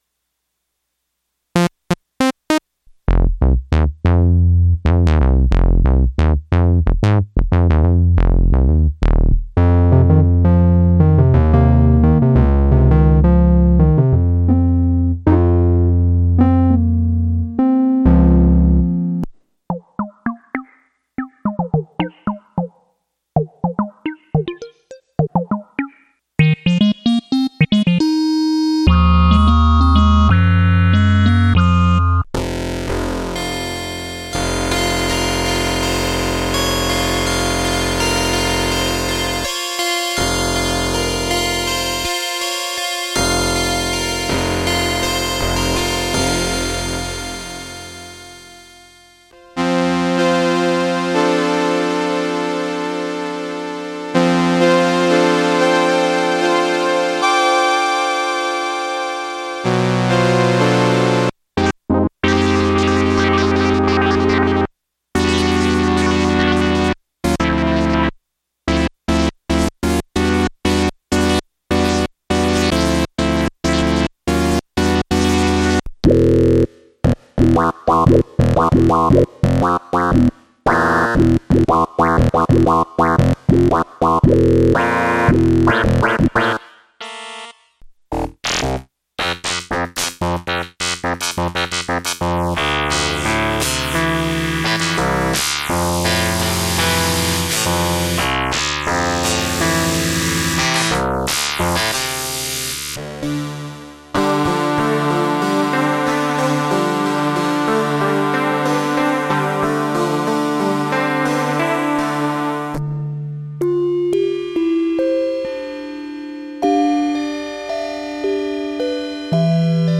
Playing is kind of crap but maybe someone will hear something in one of these patches that sparks an idea.
I couldn’t help but go for a little knob twidle starting around 4:00 minutes.
I really like the shoegazy ambiance you make with the teo5 thank you for sharing